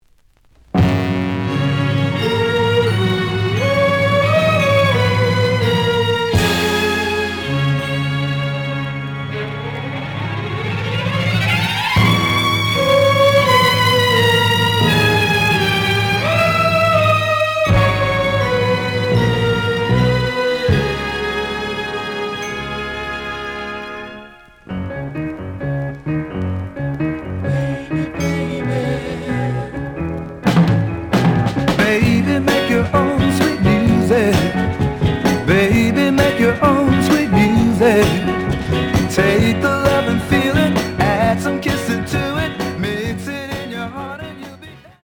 (Mono)
The audio sample is recorded from the actual item.
●Genre: Soul, 60's Soul